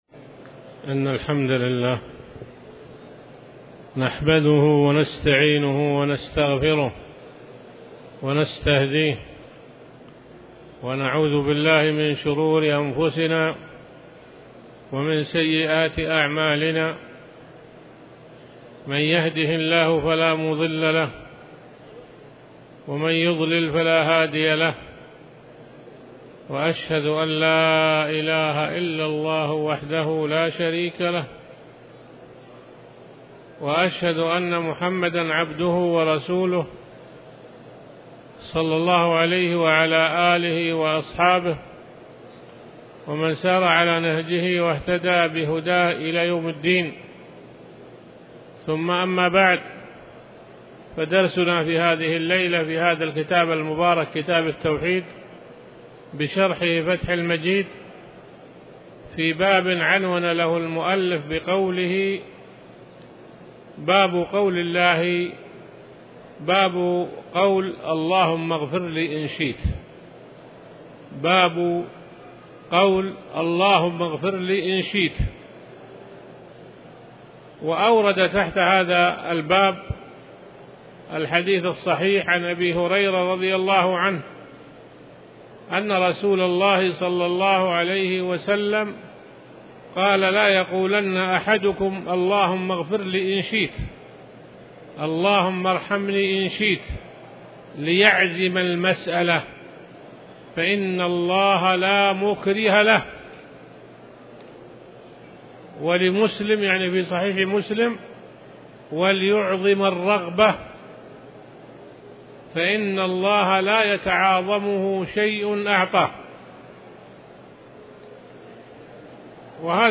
تاريخ النشر ١ محرم ١٤٤٠ هـ المكان: المسجد الحرام الشيخ